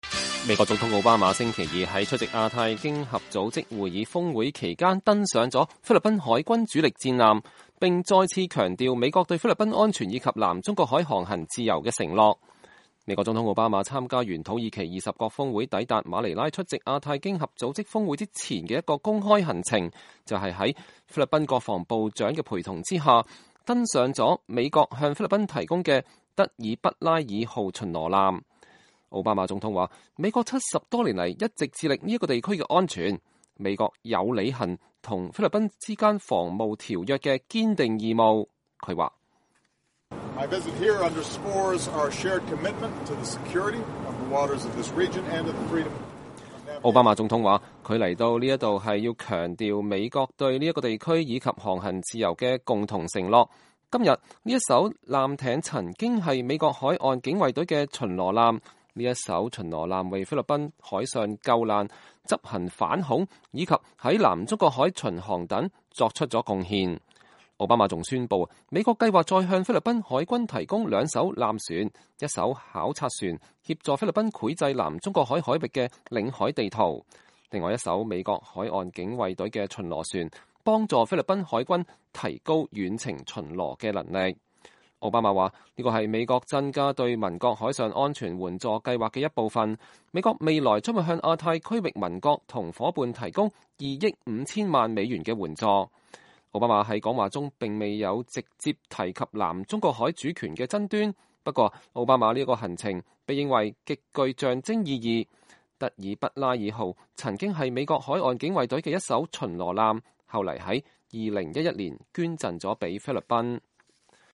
奧巴馬登上菲律賓的“德爾畢拉爾”號巡邏艦後發表講話